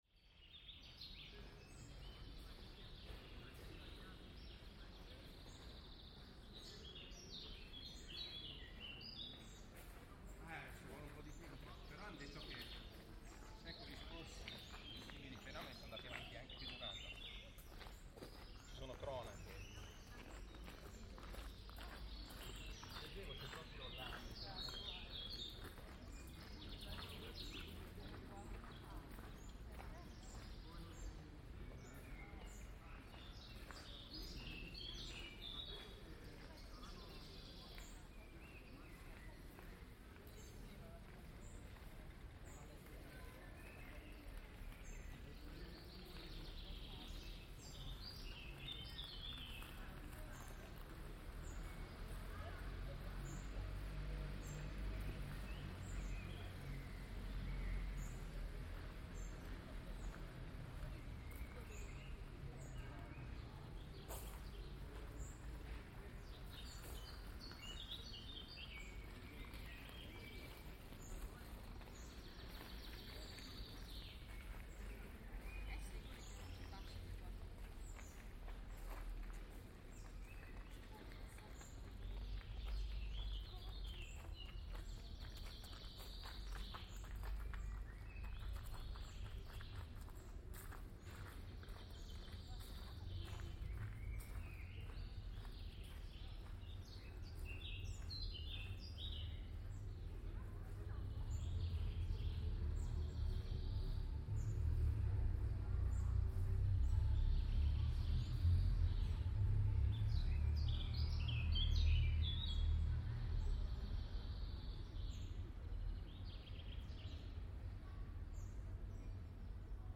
This recording captures birdsong and locals passing through a quiet public garden in central Mantua.